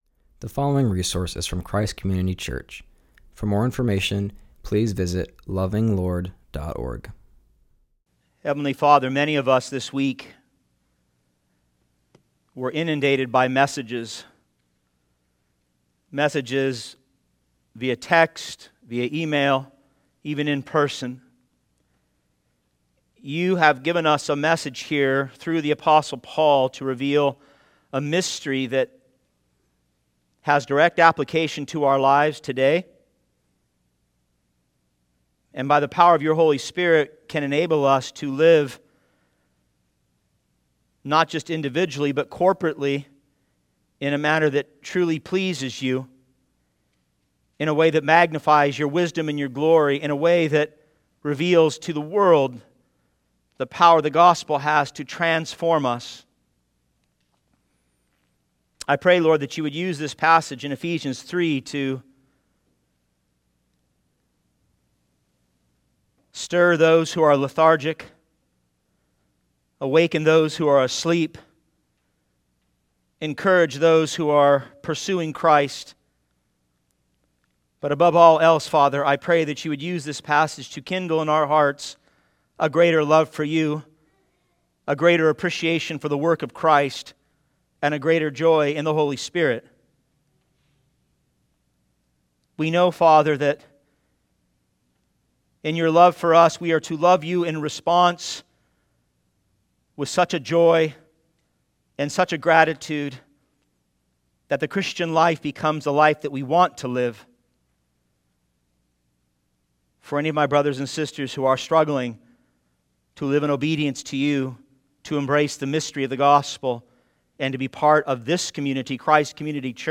continues our series and preaches from Ephesians 3:1-13.